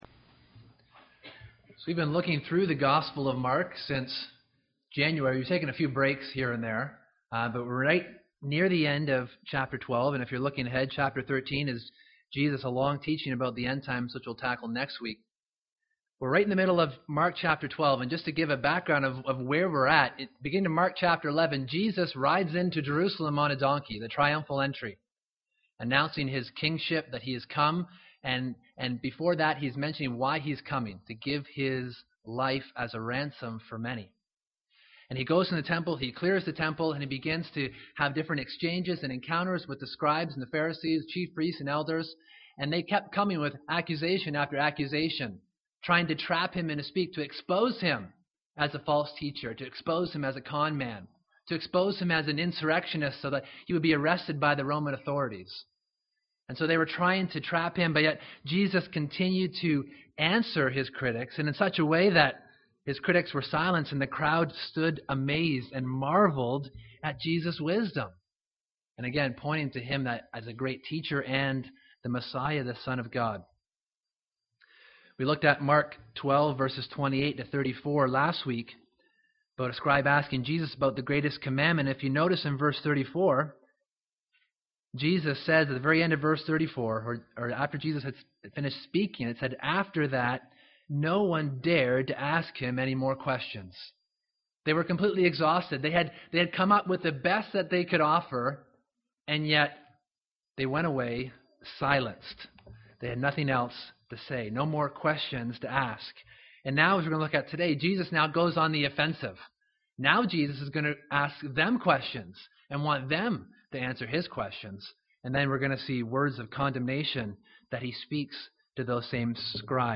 September 14, 2014 ( Sunday AM ) Bible Text